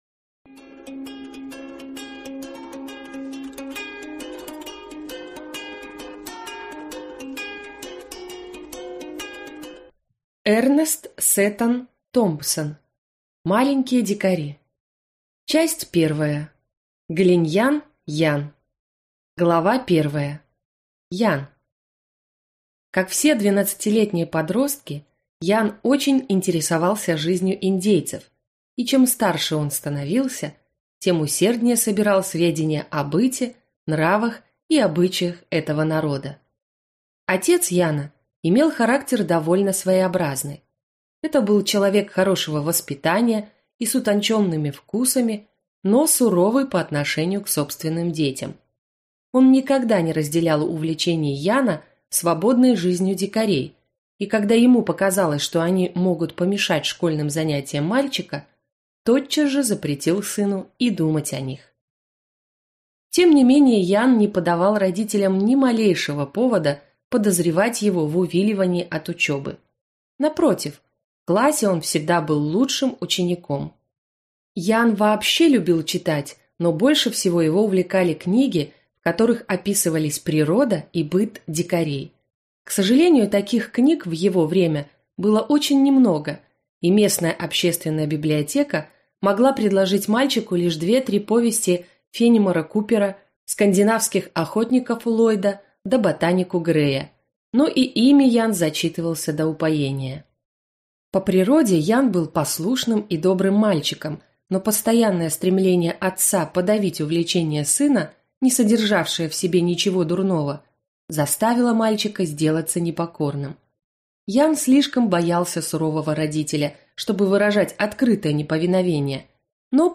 Аудиокнига Маленькие дикари | Библиотека аудиокниг